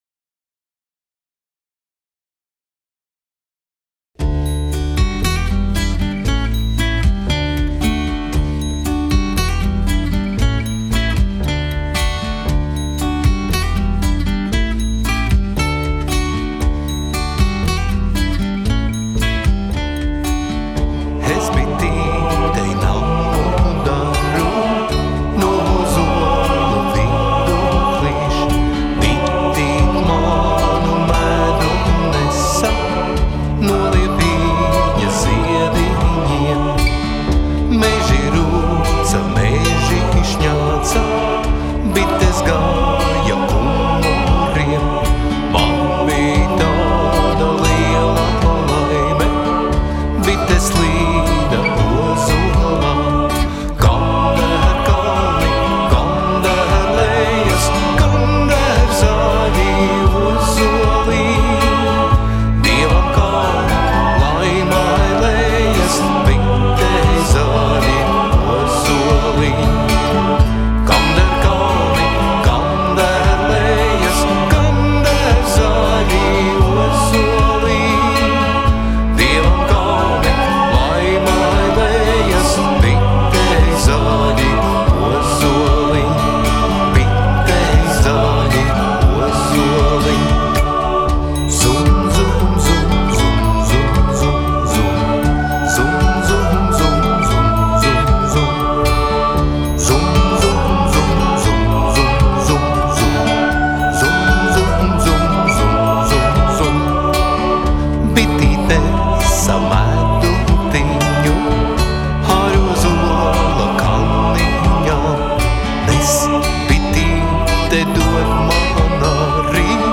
kora apdari